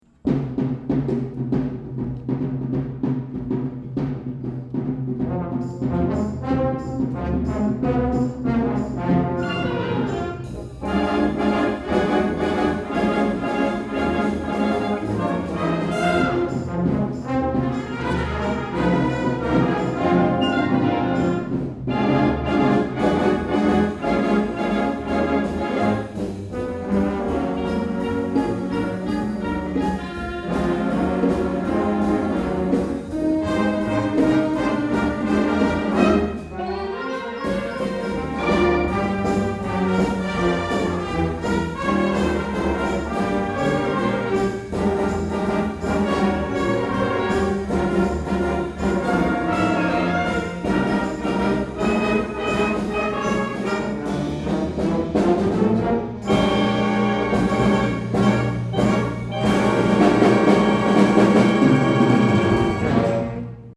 Below are music excerpts from some of our concerts.
2009 Summer Concert
June 14, 2009 - San Marcos High School